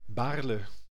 Baarle (Dutch pronunciation: [ˈbaːrlə]
Nl-Baarle.ogg.mp3